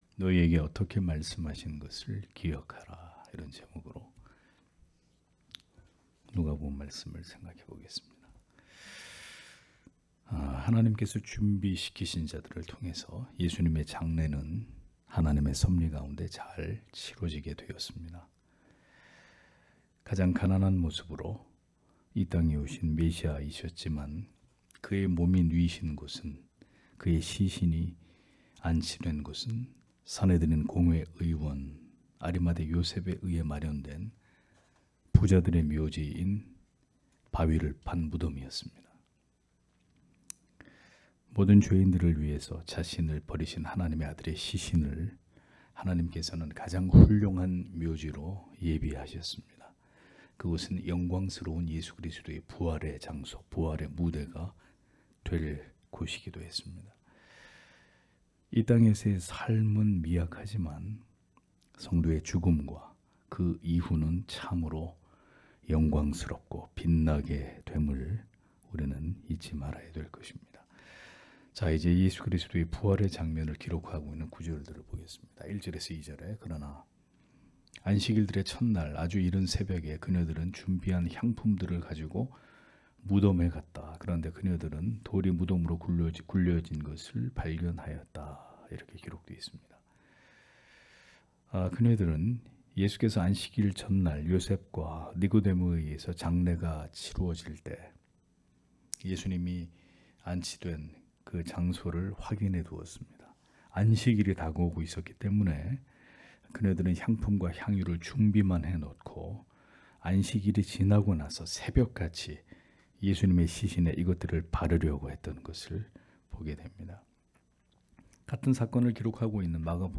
금요기도회 - [누가복음 강해 182] '너희에게 어떻게 말씀하신 것을 기억하라' (눅 24장 1- 10절)